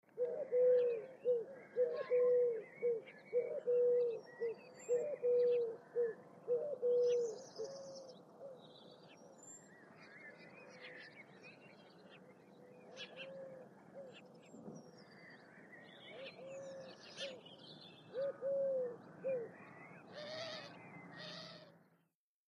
Ringed doves ( U.K.)